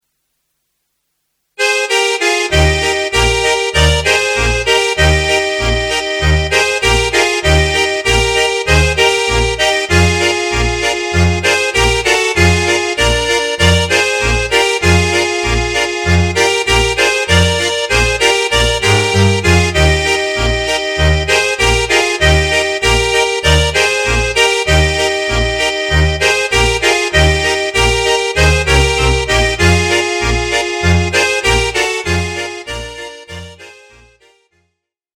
Eb-Dur